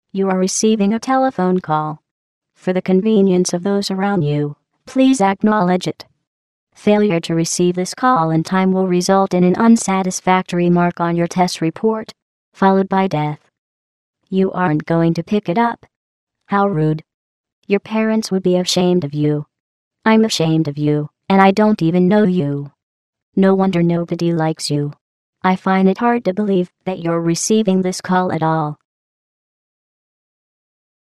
I thought I'd share these cool Portal ringtones for any Portal fans out there.  8)
Call:
GlaDOS-Call.mp3